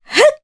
Juno-Vox_Jump_jp.wav